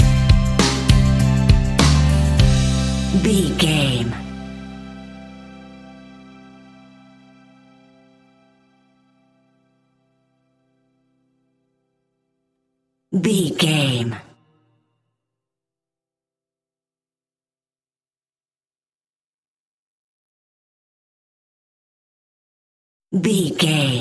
Ionian/Major
calm
happy
energetic
smooth
soft
uplifting
electric guitar
bass guitar
drums
pop rock
indie pop
instrumentals
organ